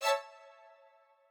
strings5_23.ogg